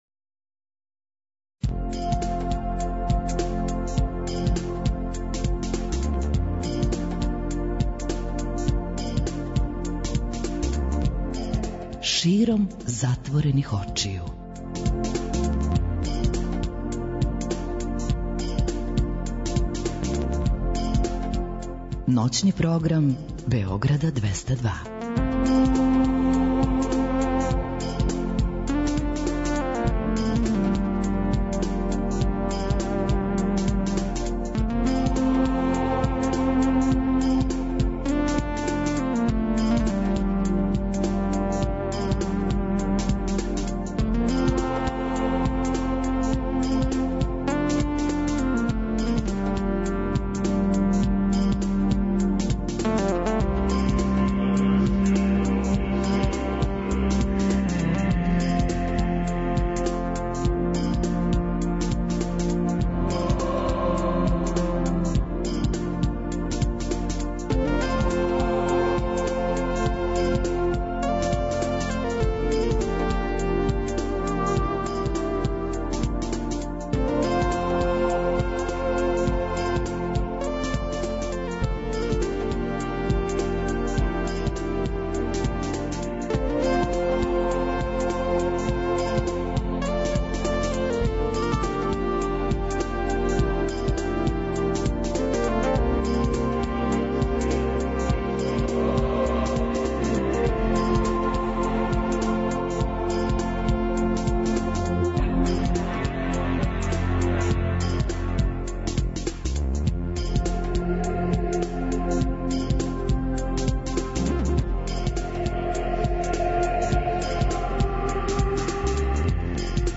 Ноћни програм Београда 202.